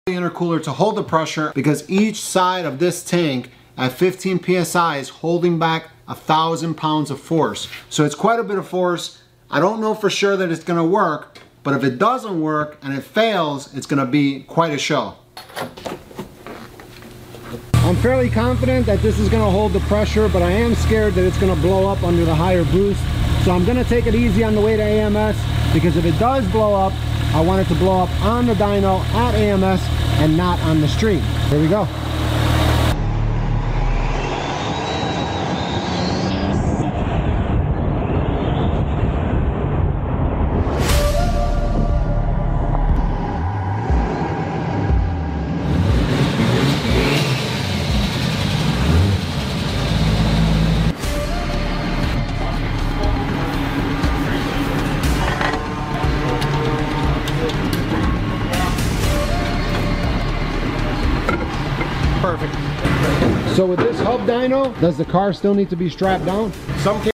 See Thru Intercooler In Slow sound effects free download
See Thru Intercooler In Slow Motion - (Dyno Run High Boost)